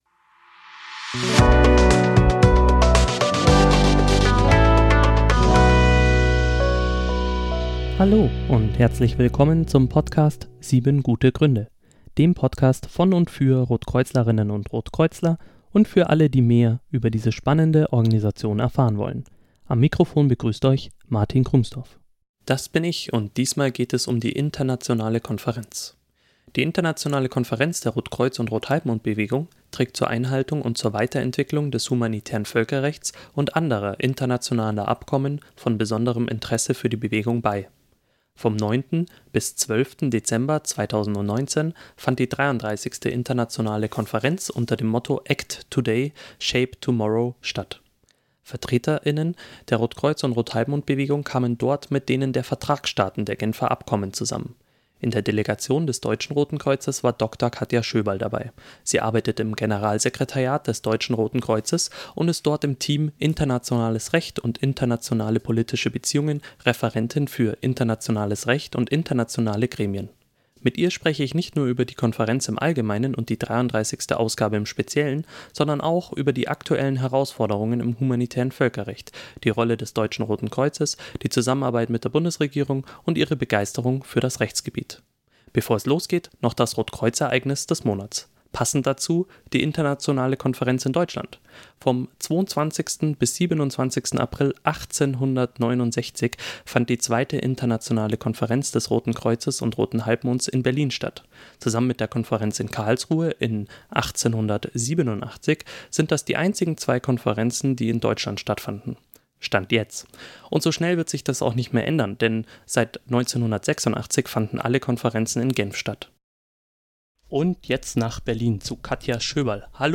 Mit ihr spreche ich am Telefon nicht nur über die Konferenz im Allgemeinen und die 33. Ausgabe im Speziellen, sondern auch über aktuellen Herausforderungen im Humanitären Völkerrecht, die Rolle des Deutschen Roten Kreuzes, die Zusammenarbeit mit der Bundesregierung und ihre Begeisterung für das Rechtsgebiet.